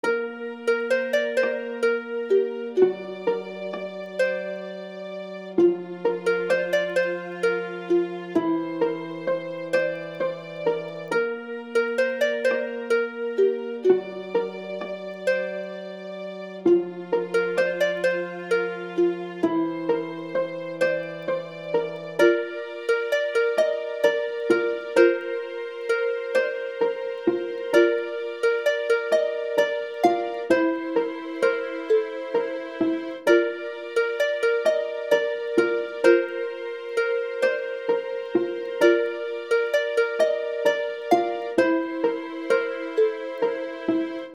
This is a short RPG piece that I composed and decided to share!